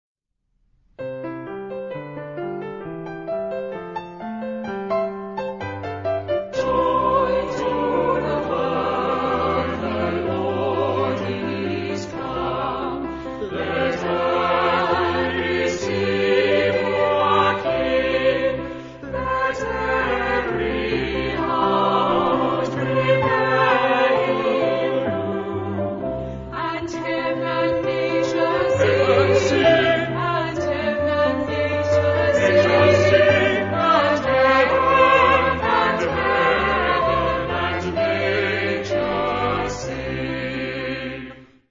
Genre-Style-Forme : Sacré ; Chant de Noël
Caractère de la pièce : joyeux
Type de choeur : SAAB OU SATB  (4 voix mixtes )
Instrumentation : Piano  (1 partie(s) instrumentale(s))
Tonalité : do majeur